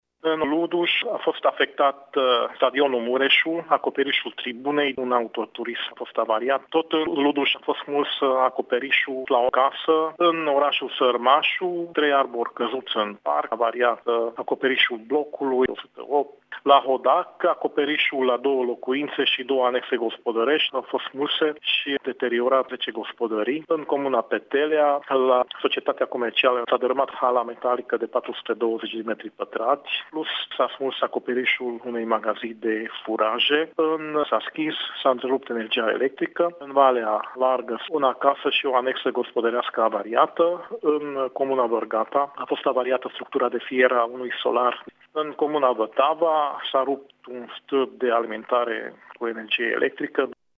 Subprefectul a prezentat pentru postul nostrum de radio bilanțul provizoriu al pagubelor produse de furtuna de aseară: